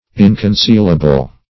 Search Result for " inconcealable" : The Collaborative International Dictionary of English v.0.48: Inconcealable \In`con*ceal"a*ble\, a. Not concealable.